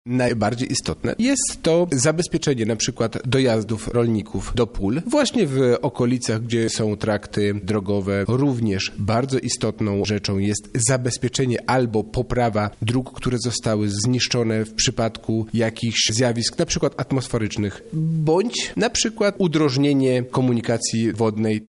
– mówi Robert Gmitruczuk,  Wicewojewoda Lubelski.